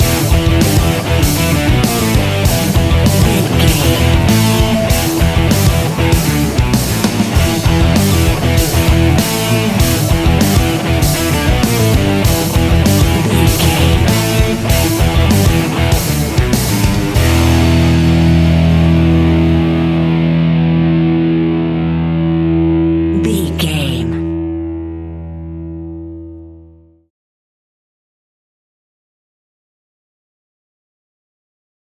Ionian/Major
energetic
driving
heavy
aggressive
electric guitar
bass guitar
drums
hard rock
heavy metal
blues rock
distortion
distorted guitars
hammond organ